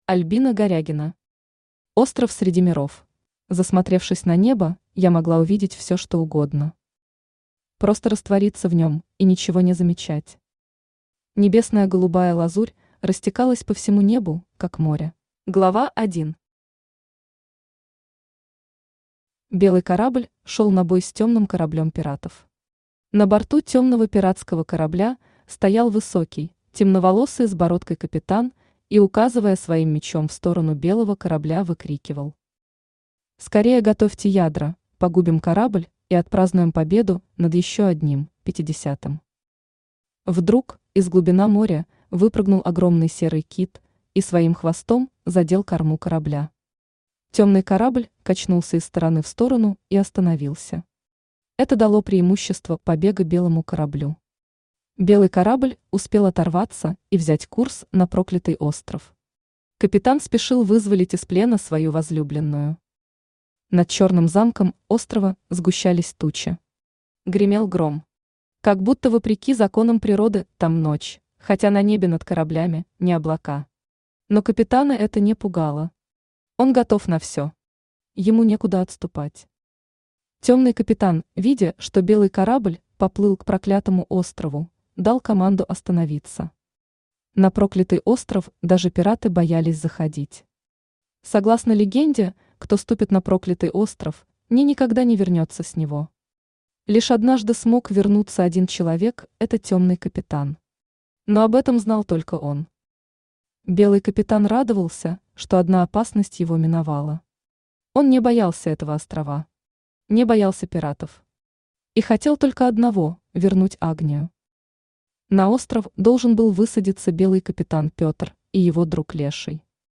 Aудиокнига Остров среди миров Автор Альбина Горягина Читает аудиокнигу Авточтец ЛитРес.